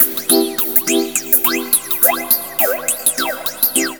PIANOFXLP1-L.wav